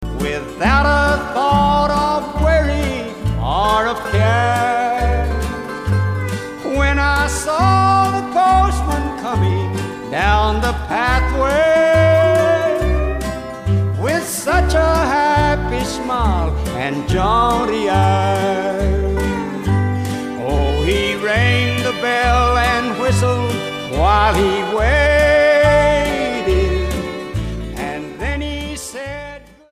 STYLE: Country
hauntingly plaintive mountain style